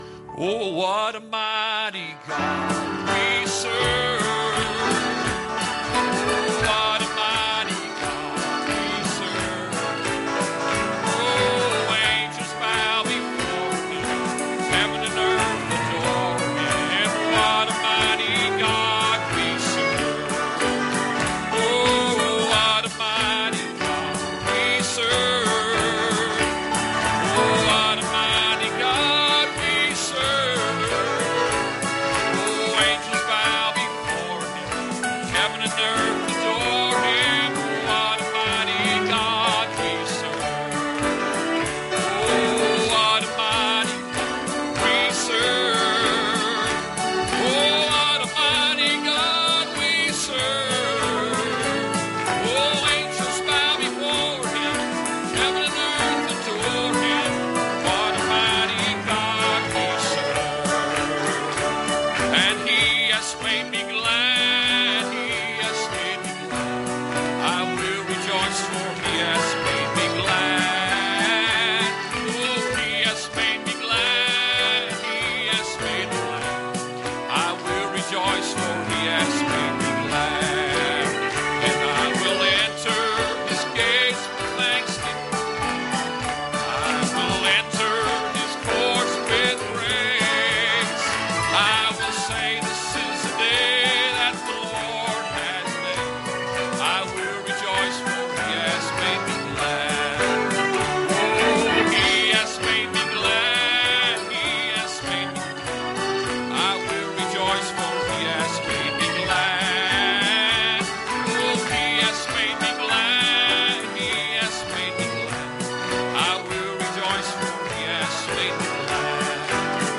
Series: Sunday Evening Services Passage: Hebrews 10:35-39 Service Type: Sunday Evening